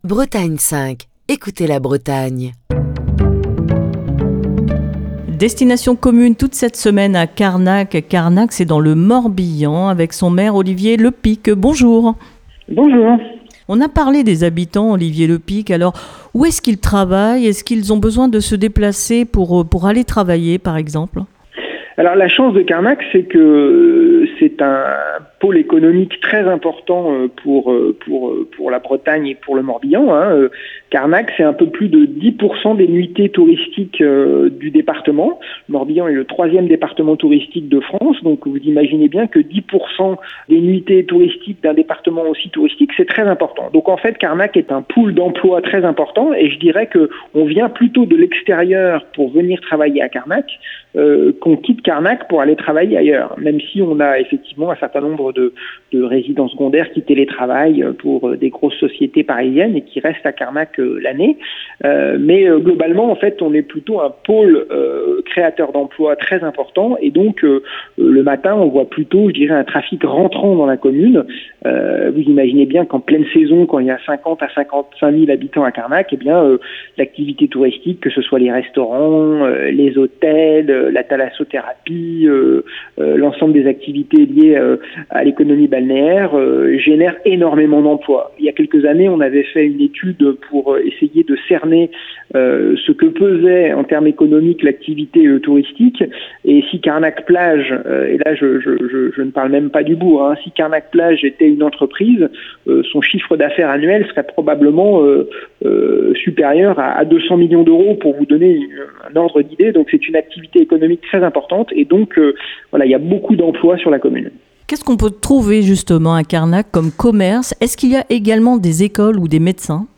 Cette semaine, Destination commune pose ses micros à Carnac, dans le Morbihan.